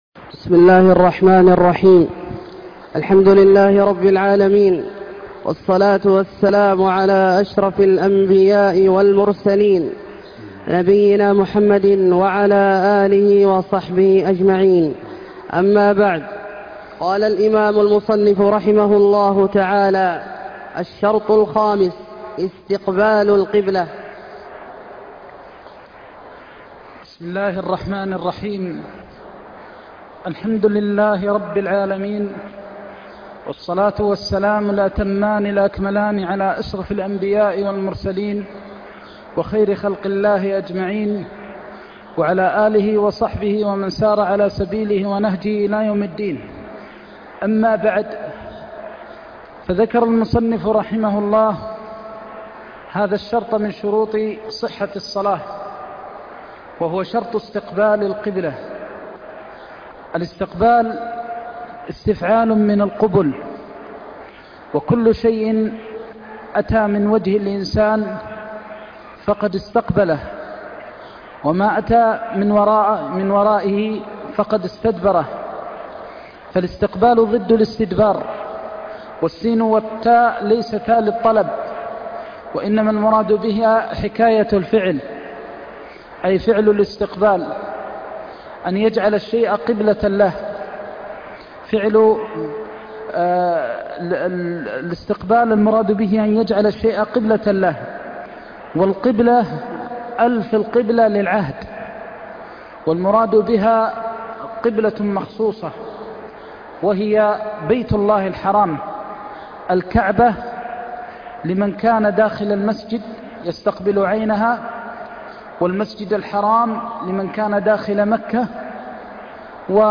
درس عمدة الفقه